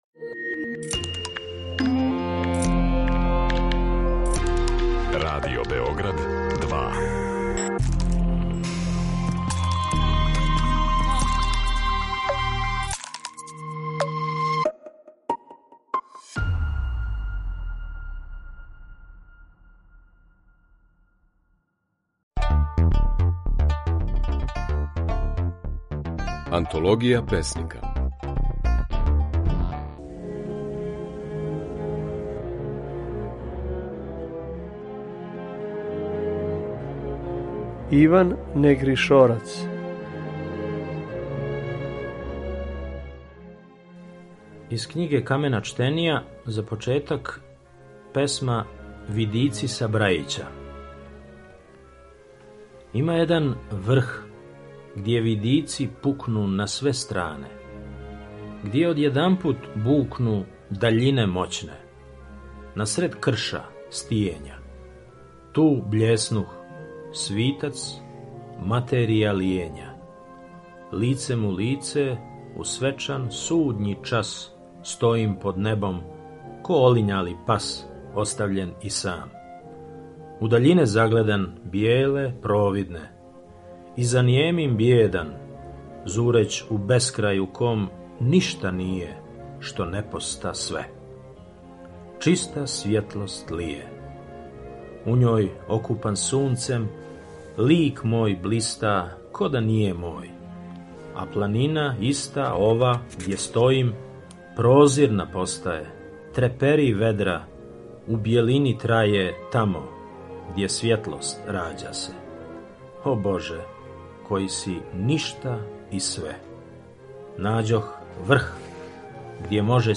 Своје стихове говори песник Иван Негришорац.